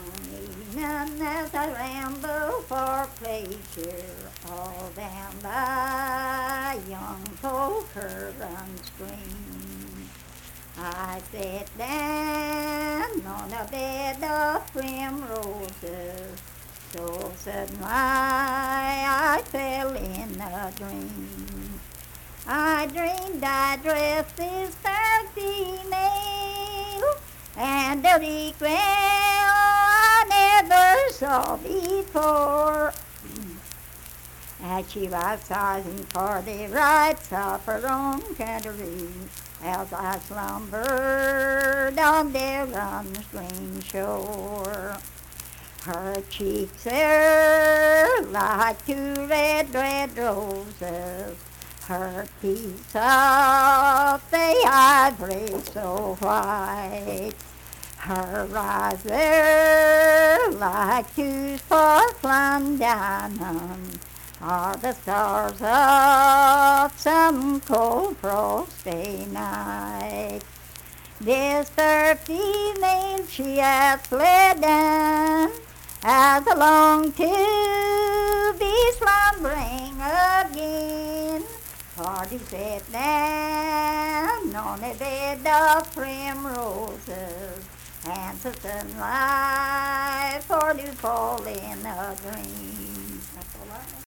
Unaccompanied vocal music performance
Verse-refrain 4(4).
Voice (sung)